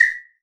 HWHISTLELW.wav